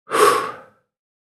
Sigh-sound-effect.mp3